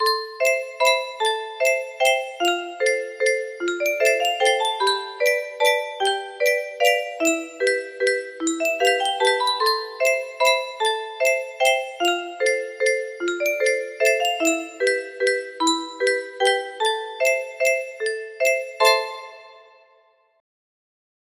unknown music box melody